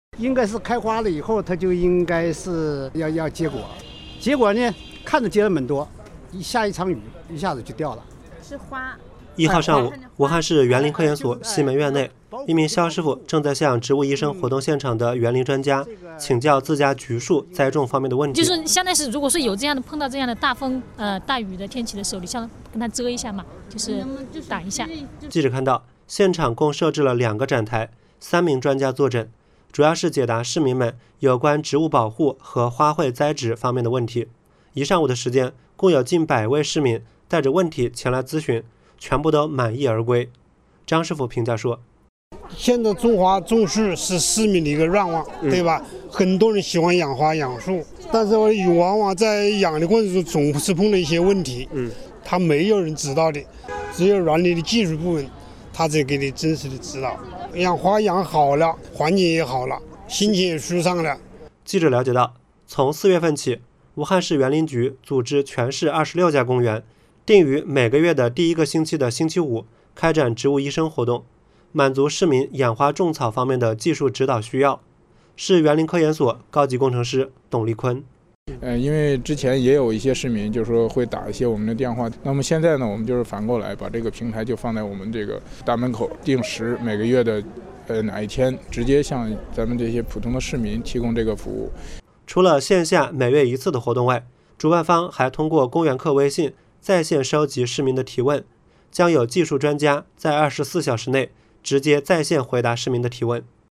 4月1日，为响应26家公园联合举行“植物医生”活动，我所在西门出口处举办第一期活动，对居民养花种草方面的问题，进行一一解答。请听武汉新闻广播（调频88.4）朝闻快报栏目记者对此次活动的报道：